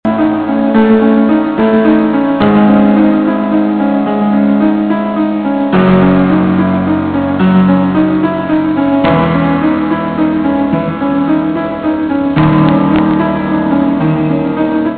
piano blues jazz